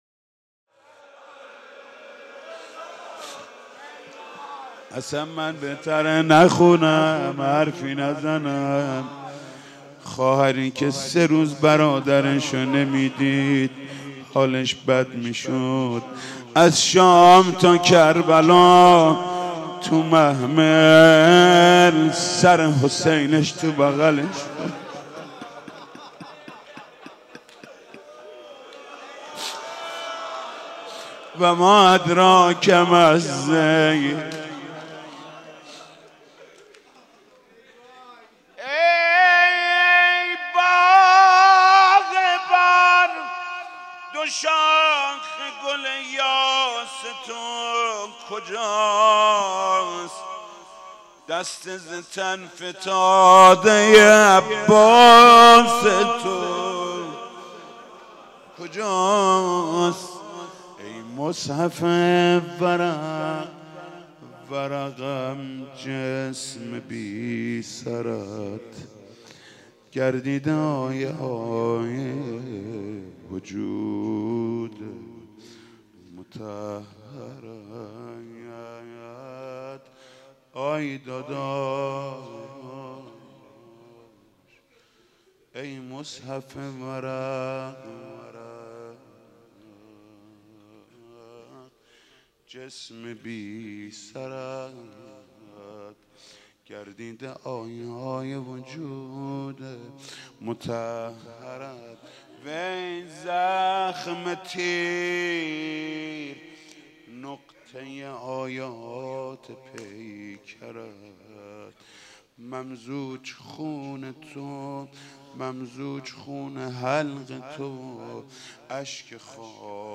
اربعین - روضه - ای باغبان دوشاخ گل یا تو کجاست